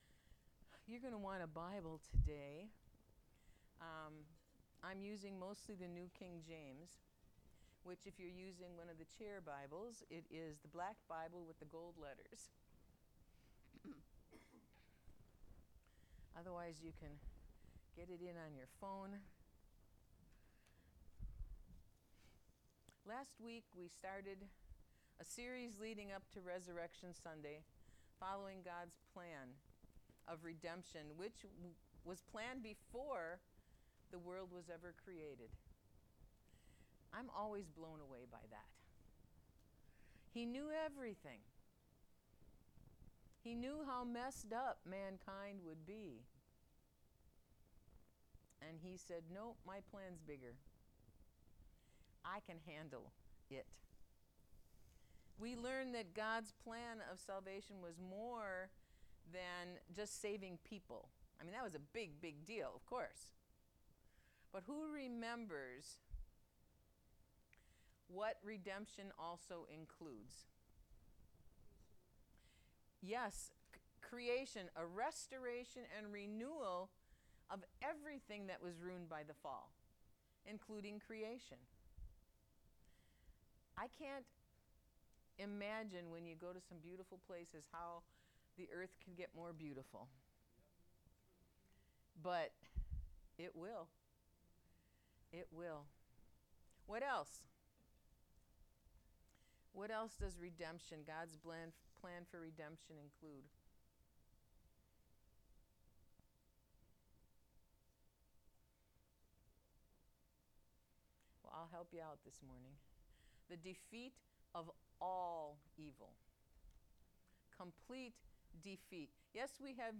Sermons | The City of Hope